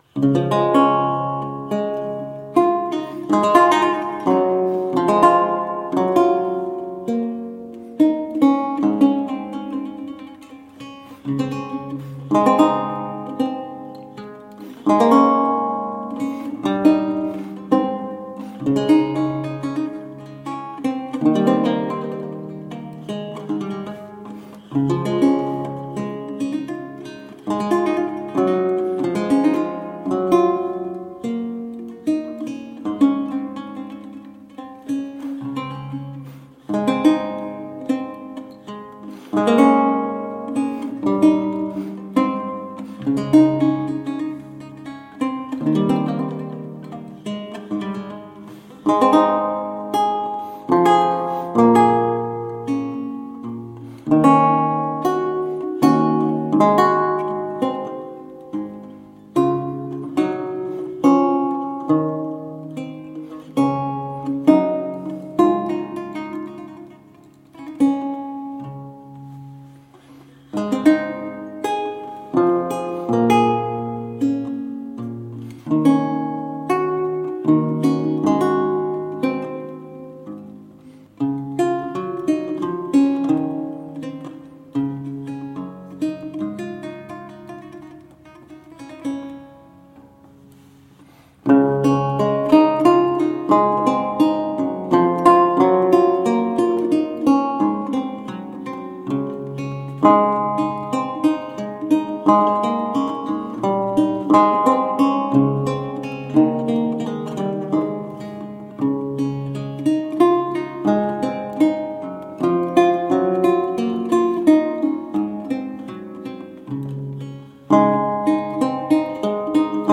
Vihuela, renaissance and baroque lute
Classical, Baroque, Renaissance, Instrumental
Lute